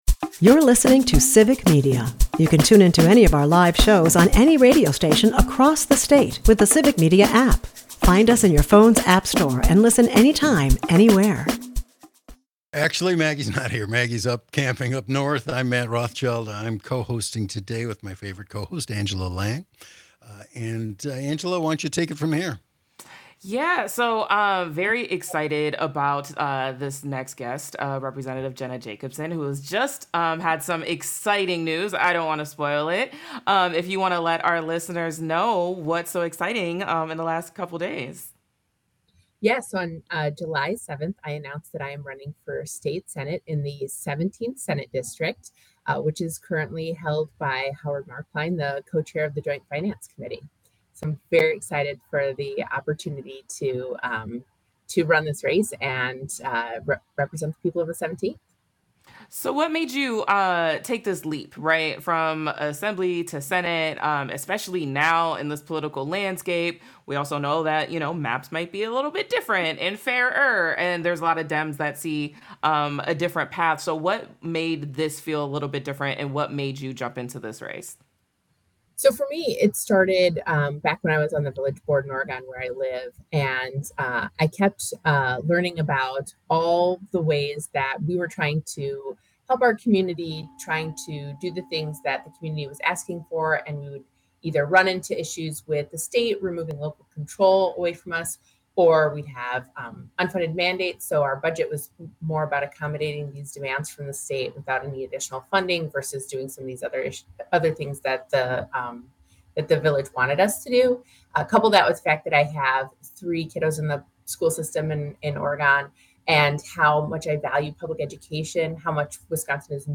They are joined by State Representative Jenna Jacobson, who recently launched her campaign for the Wisconsin State Senate. Jacobson shares her vision for a more accountable and equitable democracy, emphasizing the urgent need for fair maps and stronger investment in education, rural infrastructure, and affordable housing.